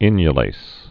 (ĭnyə-lās)